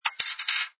sfx_put_down_beercap01.wav